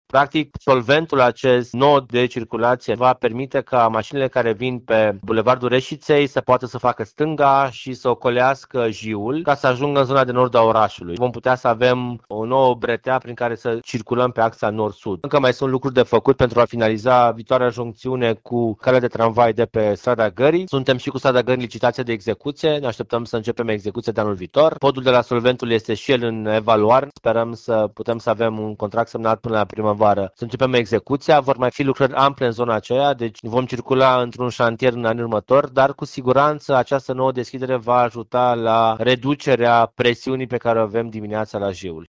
Viceprimarul Timișoarei, Ruben Lațcău, a explicat modul în care va funcționa noul nod rutier și etapele viitoare ale proiectului: